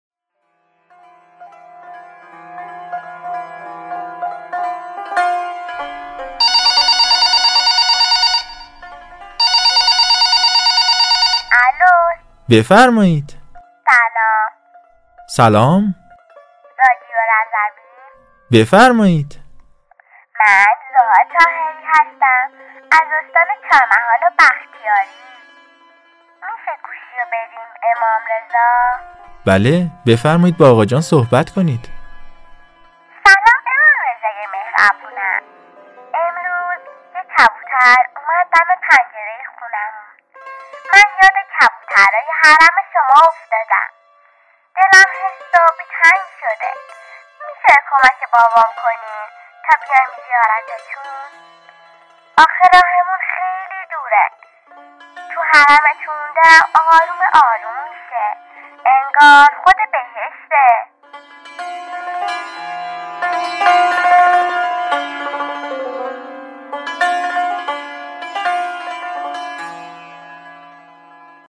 صدای کودک،تماس تلفنی،حرم امام رضا،رادیو رضوی